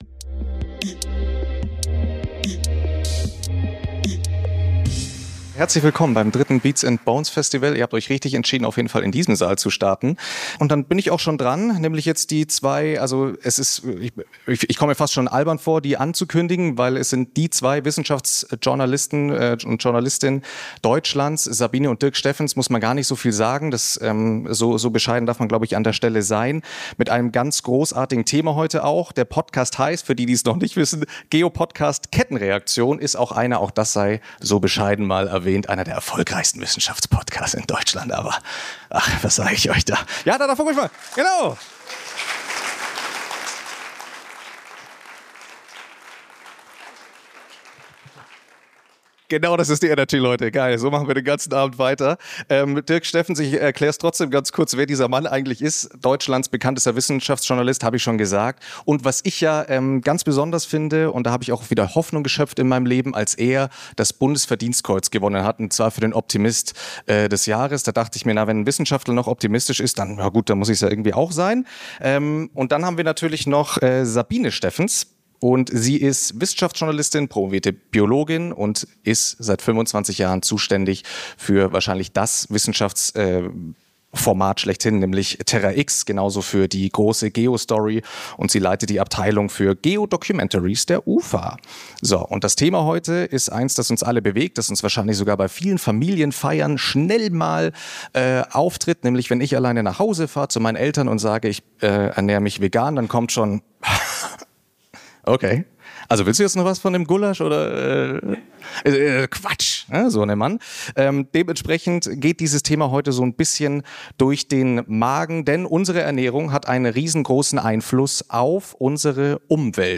#35 Wie wir die Welt gesund essen - Live vom Beats&Bones Festival Berlin